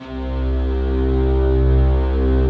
Index of /90_sSampleCDs/Optical Media International - Sonic Images Library/SI1_Swell String/SI1_Soft Swell